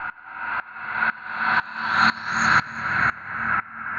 Index of /musicradar/sidechained-samples/120bpm